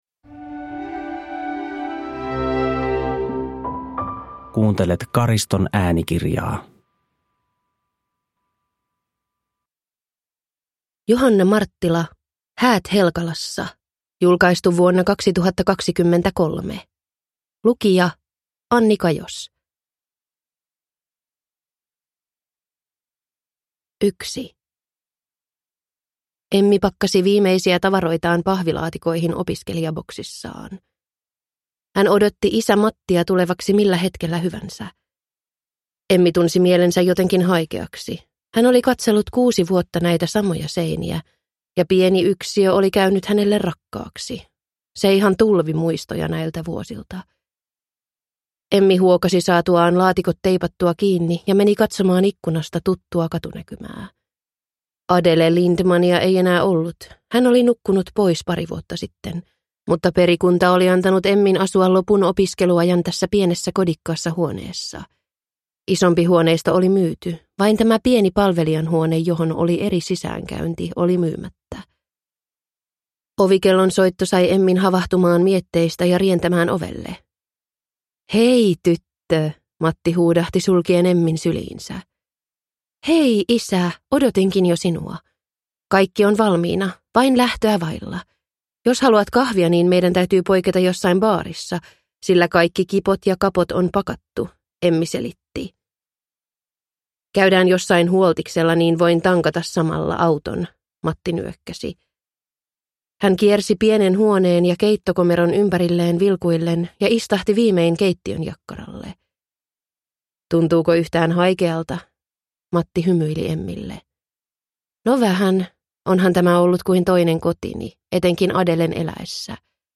Häät Helkalassa – Ljudbok – Laddas ner